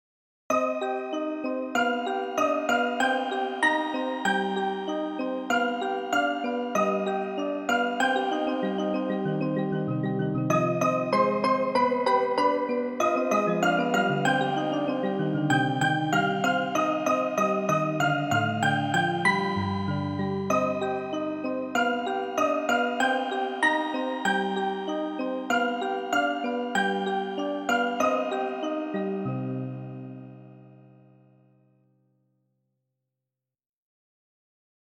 Dark arrange version of famous works